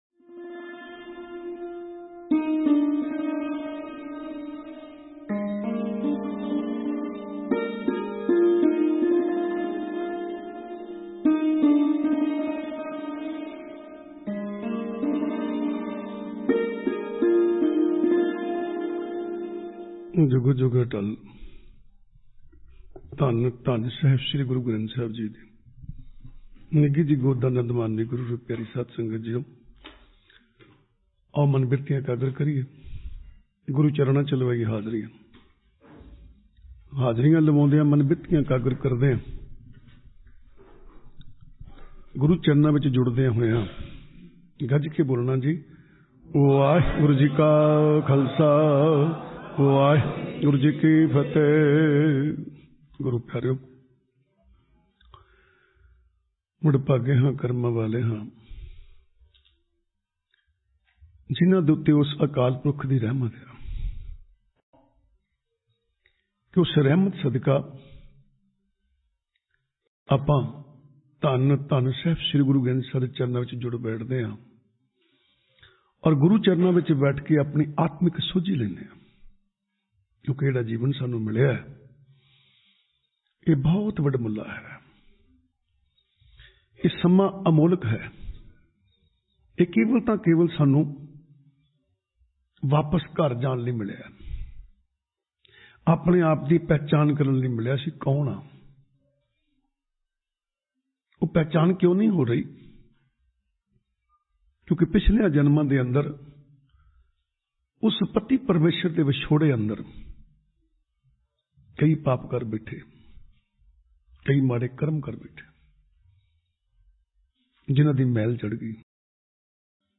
Recent Parwachan
Live from Gurdwara Bakhshish Dham - Nussi